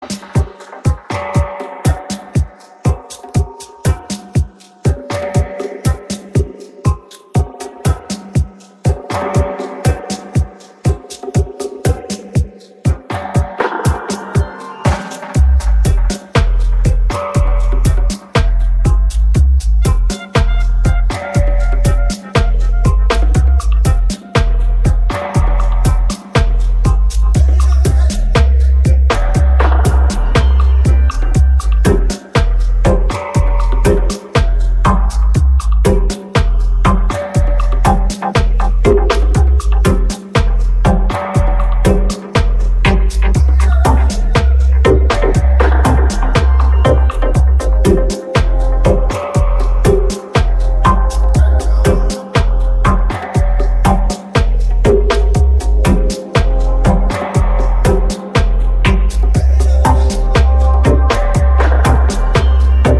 シロップのように甘くヘヴィなローエンド、郷愁に満ちたメロディカ、精霊のように漂うヴォーカル